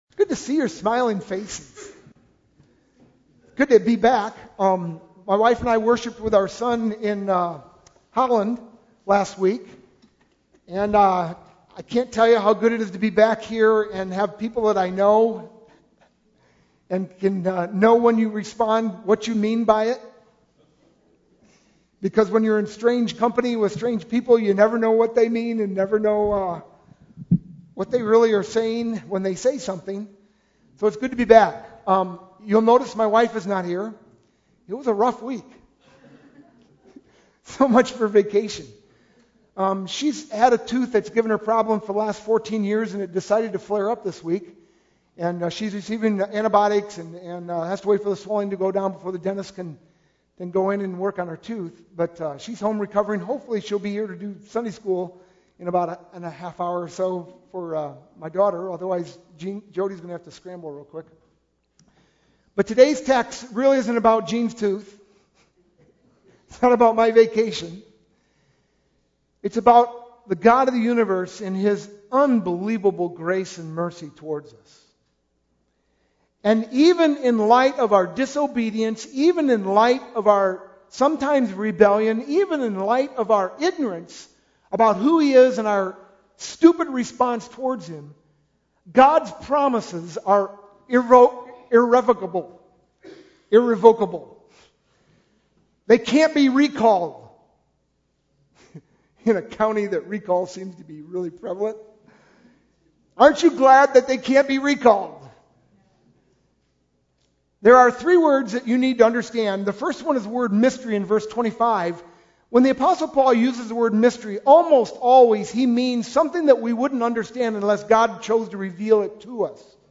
sermon-10-9-11.mp3